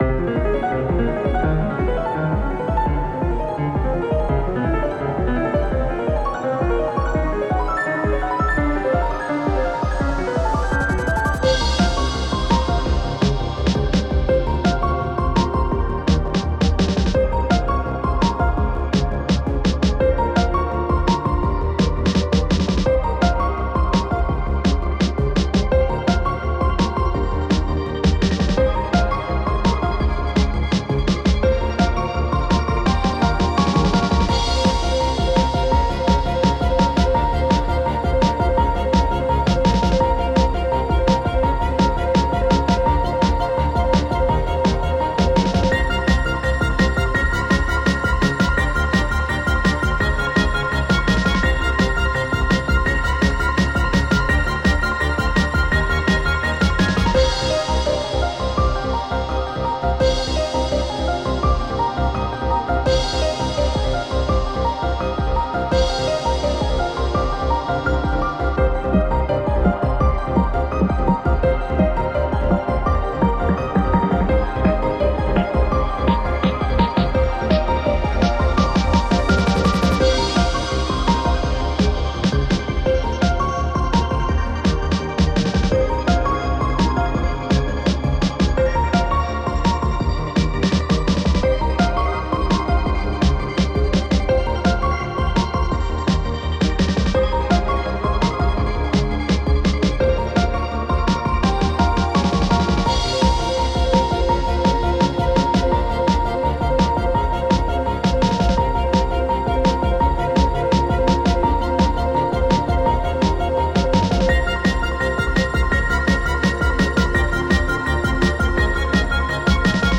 💬奇妙かつ幻想的な雰囲気のボスキャラをイメージした戦闘曲です。
どこか不安定な美しさを表現しています。